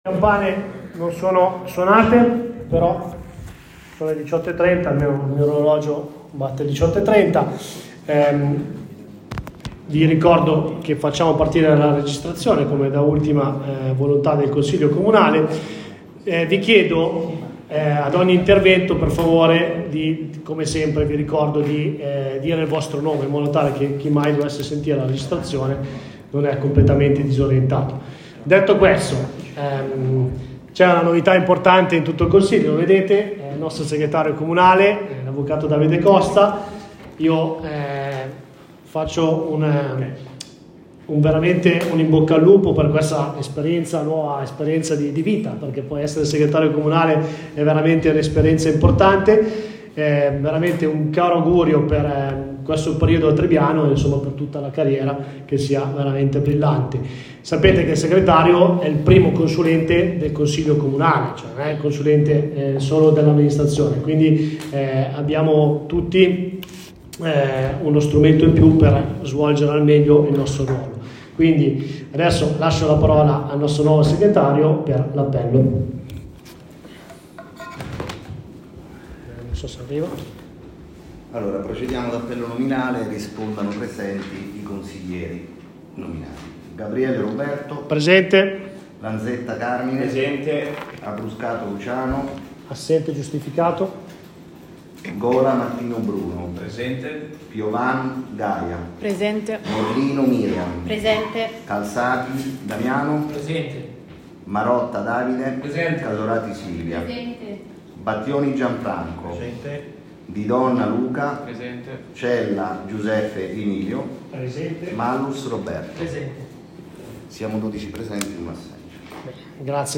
Registrazione Consiglio Comunale - Comune di Tribiano